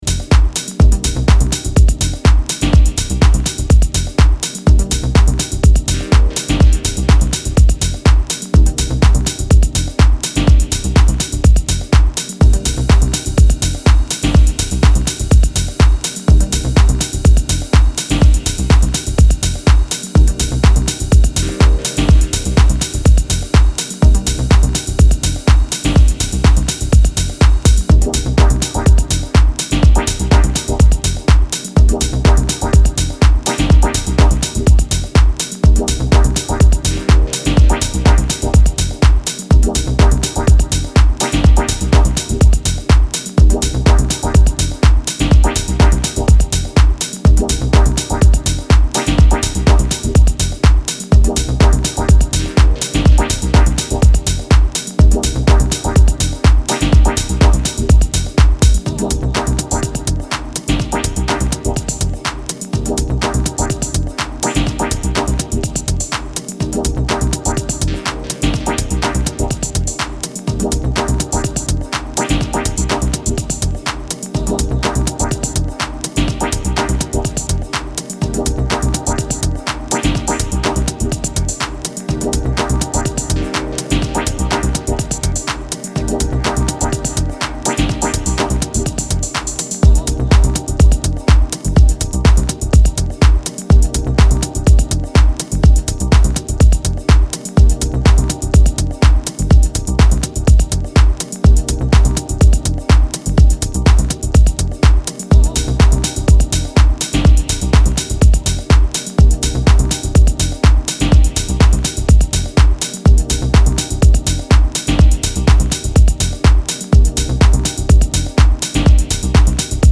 ミニマル・ハウス方面の端正な楽曲を提供しています。